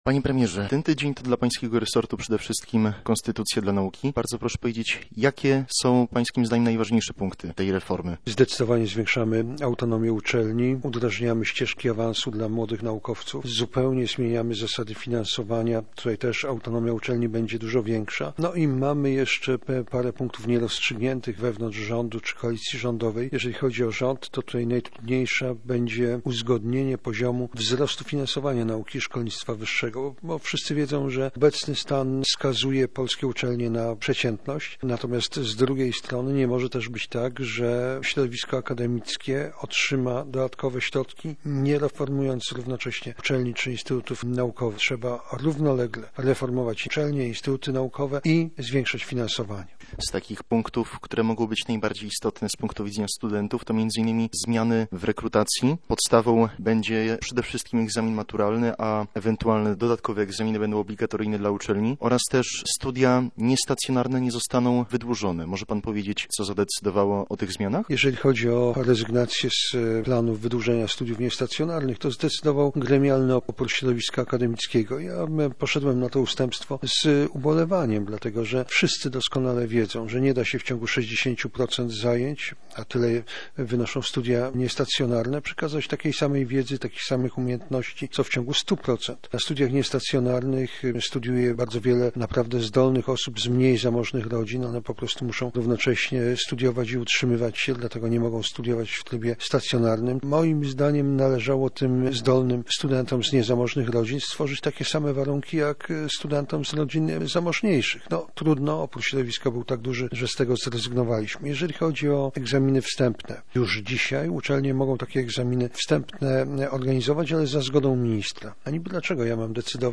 - Współpraca nauki i gospodarki niezbędna dla funkcjonowania państwa - mówi wicepremier, minister nauki oraz lider Porozumienia Jarosław Gowin.